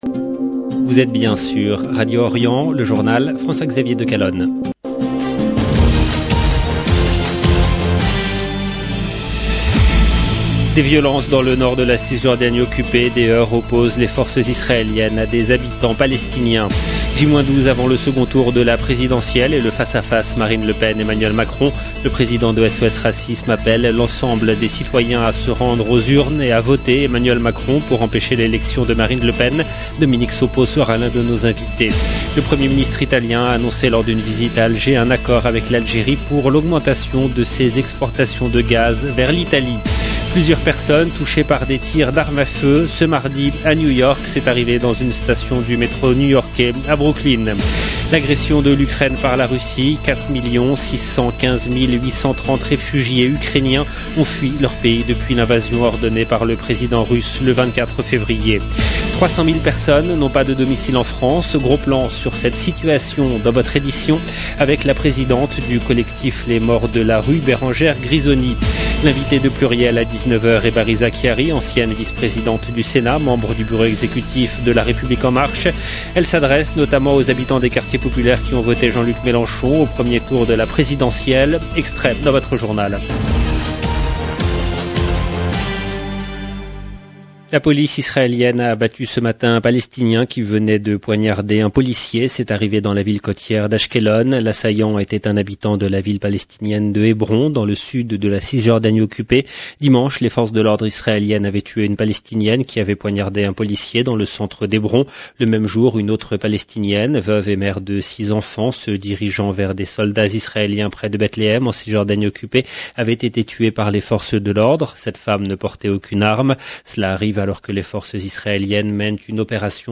Journal présenté par